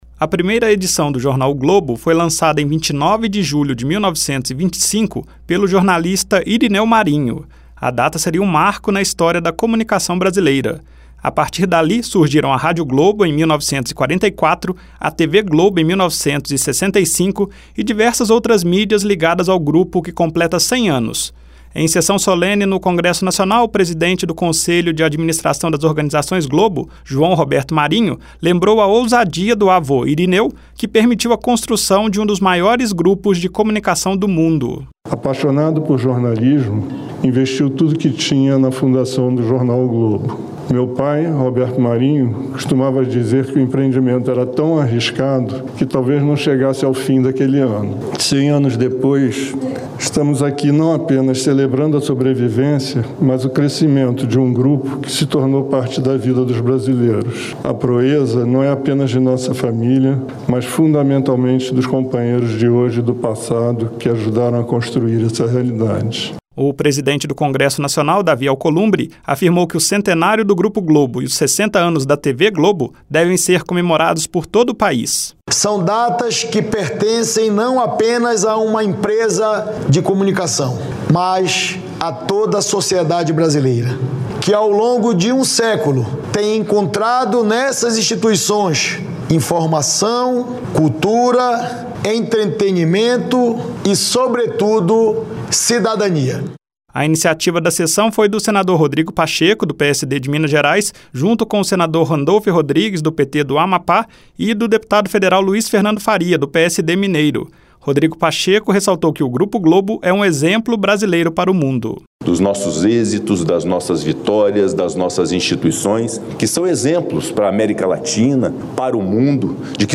Uma sessão solene do Congresso Nacional destacou os 100 anos do Grupo Globo (REQ 2/2025).